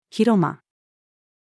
広間-female.mp3